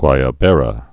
(gwīə-bĕrə)